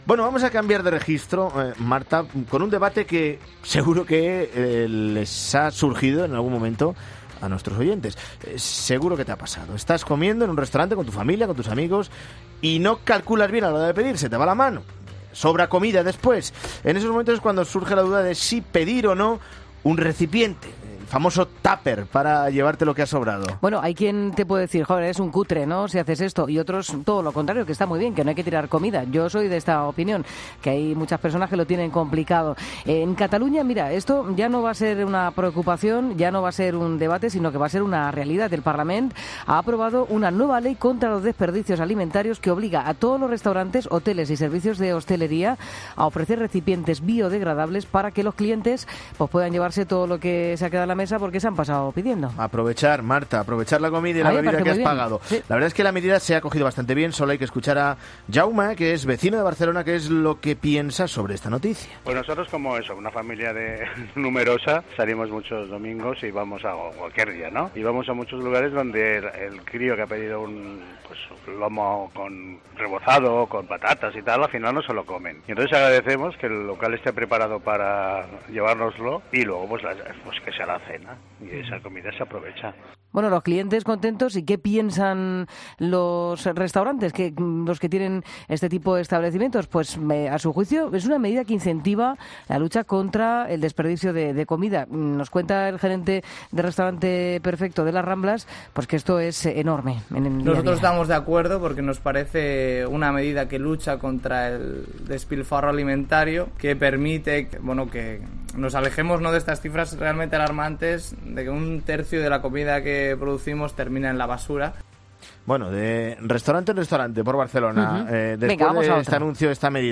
En "Mediodía COPE" hemos hablado con diferentes clientes y propietarios de restaurantes en Barcelona para saber su opinión sobre la medida.